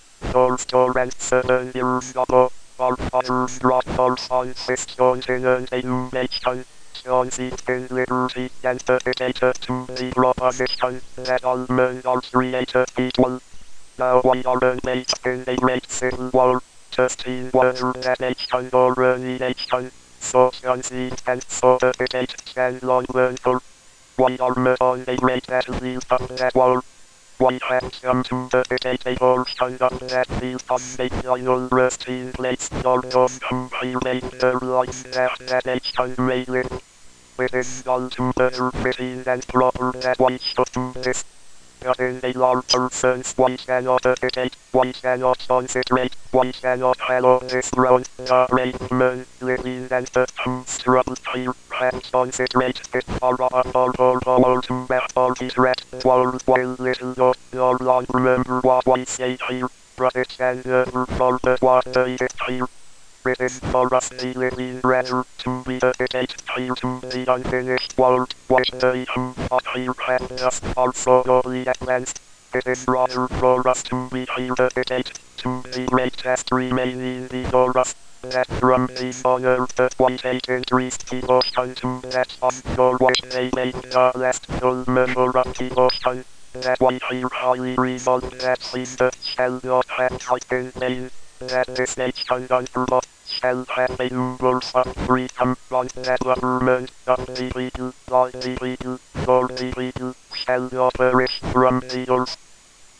new speech synthesizer for the propeller
I have recorded a few samples of it speaking to get some second opinions from the users of this forum.
I tried the Gettysburg Address and couldn't understand a single word.
• The plosives are not well-defined ("continent" sounds like "ontinent" or maybe "hontinent"
• A lot of meaning in spoken text is conveyed by pitch and this is all monotone
• The lack of pauses makes it harder to follow